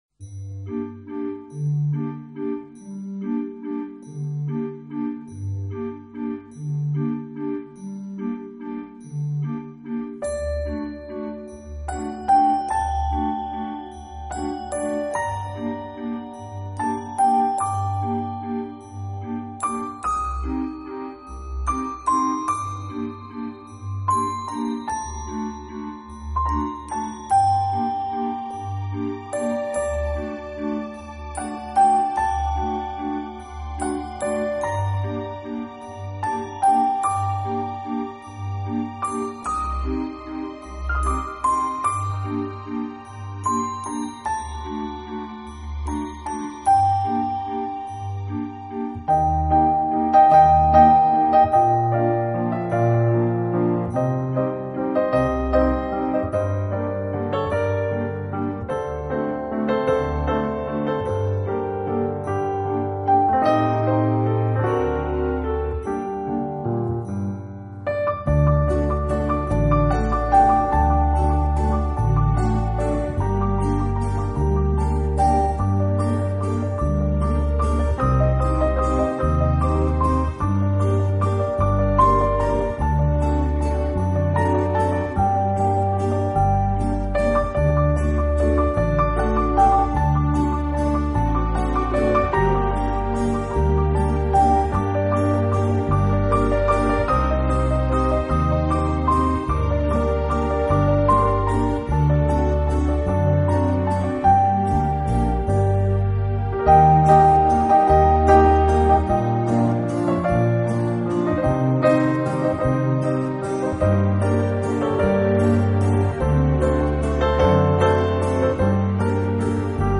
安祥的新世纪音乐气息。